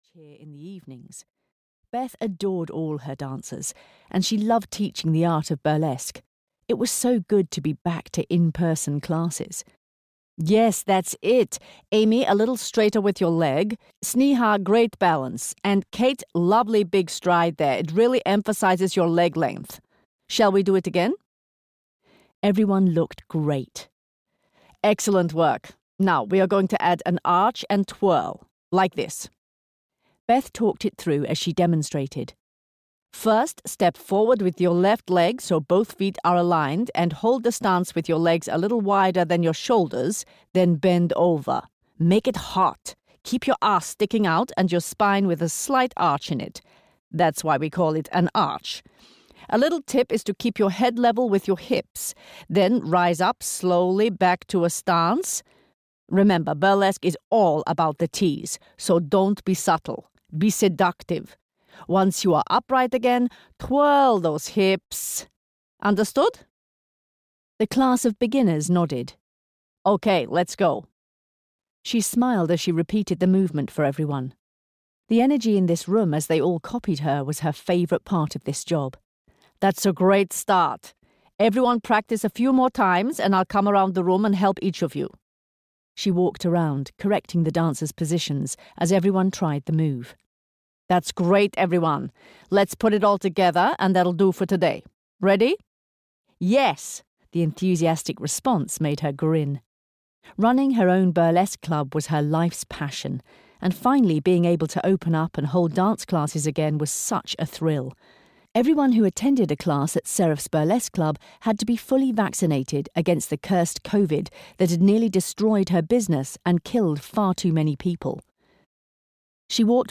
Audio knihaShow Queen (EN)
Ukázka z knihy